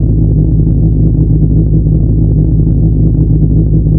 scsm_engine1w.wav